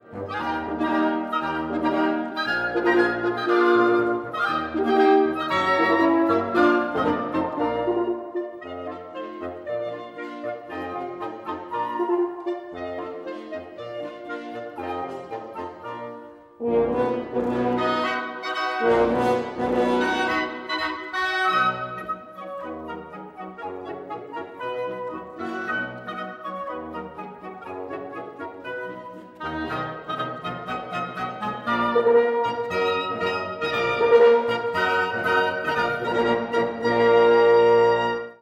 Transcription pour 10 instruments à vent